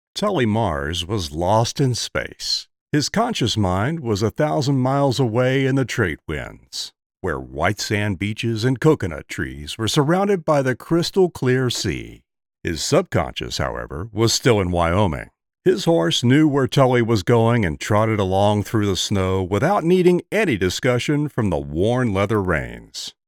Voiceover & promo samples
Audiobooks Tales from Margaritaville